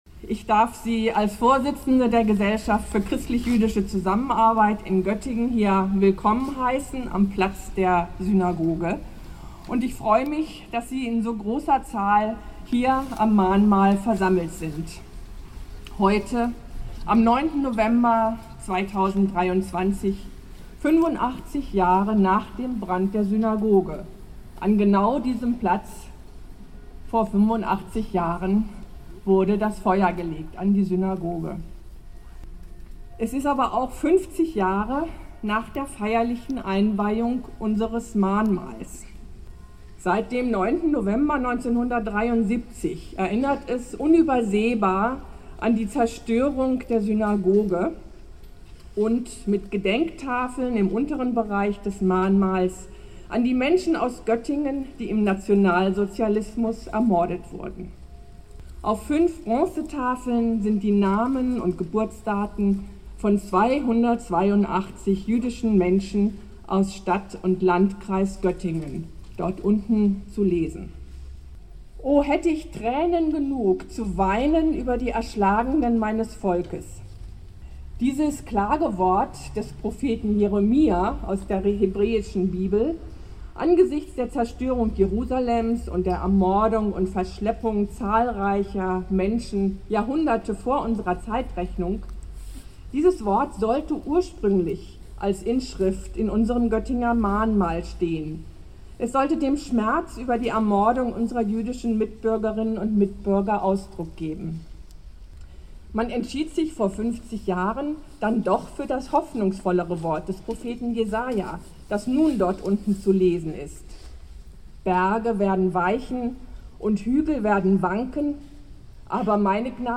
Gedenkstunde zum 9. November am Platz der Synagoge